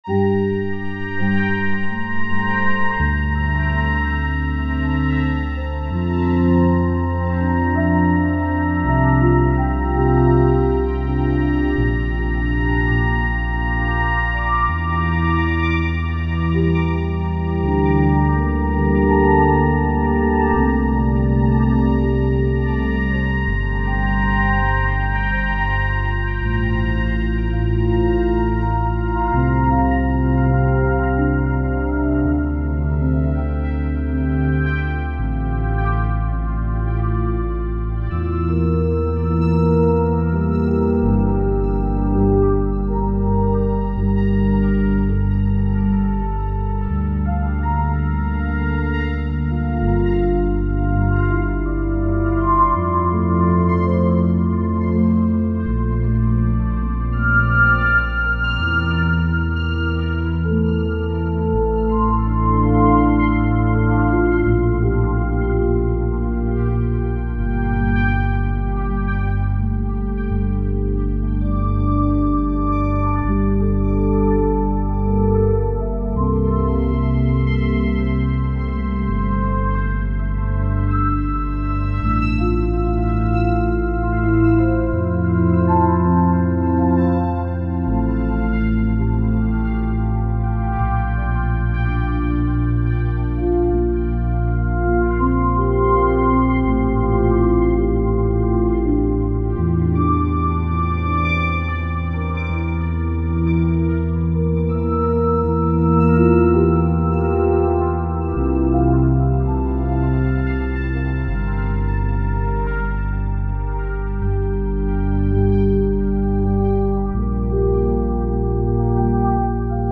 Brumenn est un duo piano électrique/flute alors que nijal est un morceau ambient/New age.
Henon ( 1.6 - 0.3) Sol (G) Dorien 82